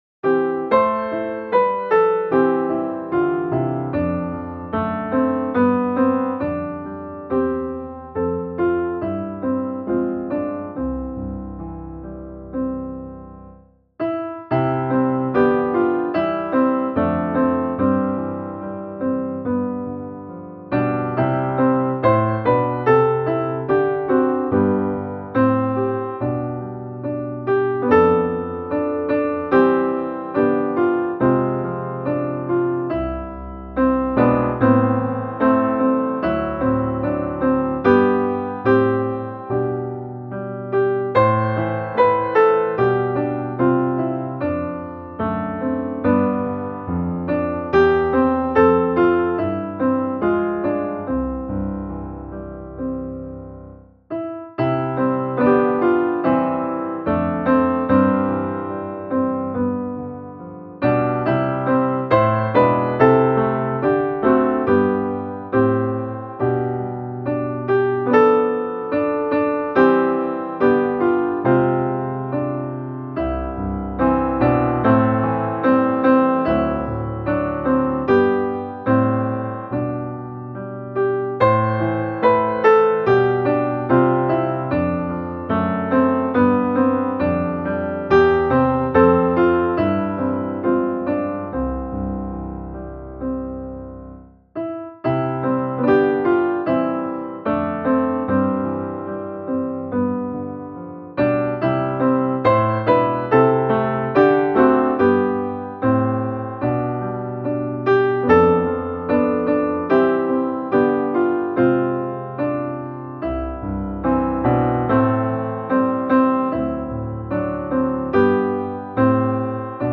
Jag kan icke räkna dem alla - musikbakgrund
Musikbakgrund Psalm